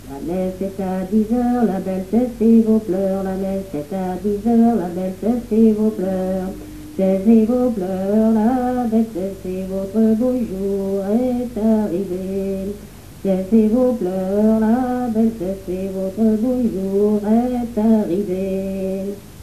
circonstance : fiançaille, noce ; circonstance : compagnonnage ;
Genre énumérative
Catégorie Pièce musicale inédite